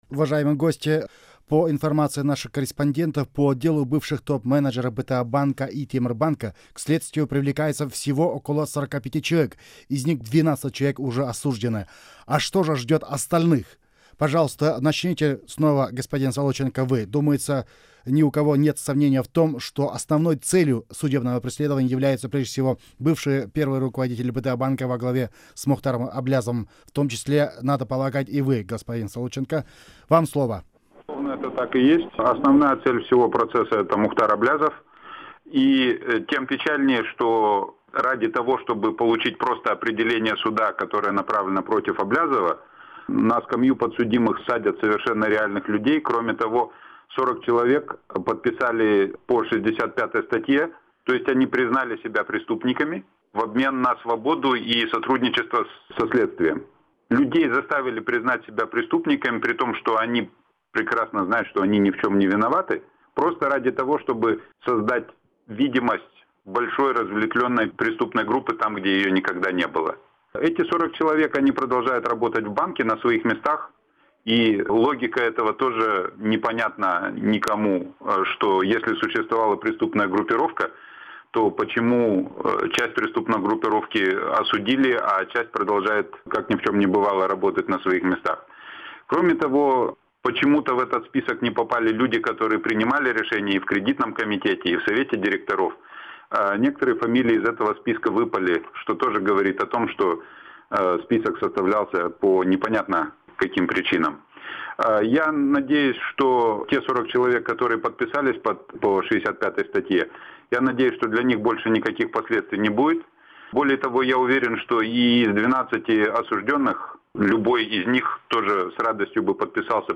Аудиозапись Круглого стола-2.MP3